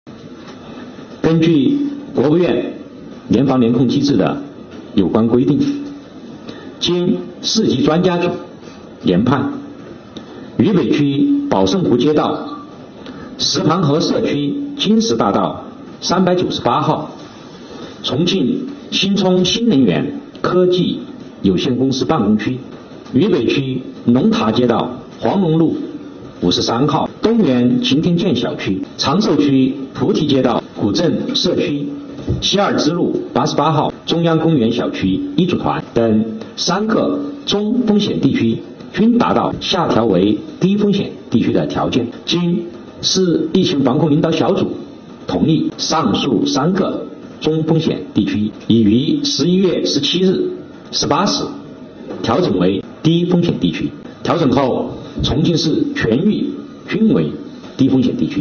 11月17日，重庆市政府新闻办举行第96场重庆市新冠肺炎疫情防控工作新闻发布会，介绍重庆疫情防控相关情况。
李 畔 市卫生健康委副主任